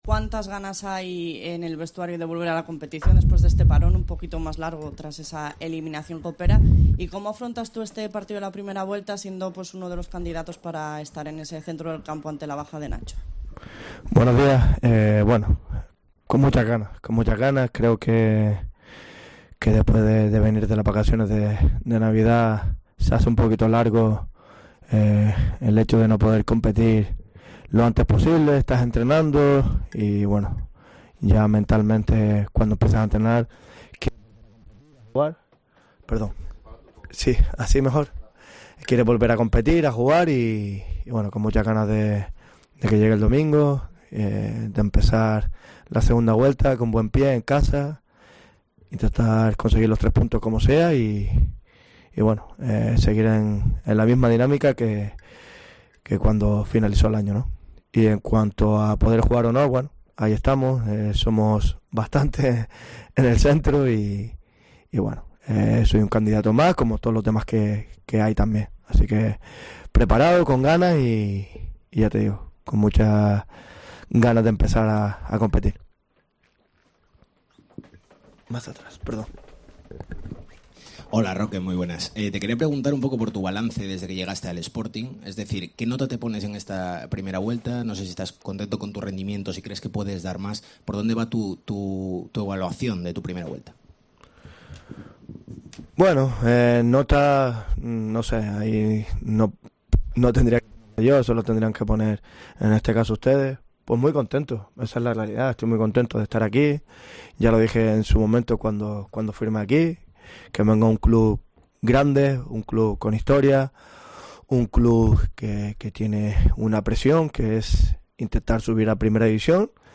Entrenamiento y rueda de prensa de Roque Mesa